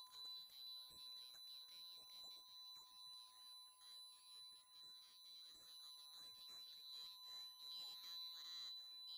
6MHz (49mバンド)　放送 26.3MB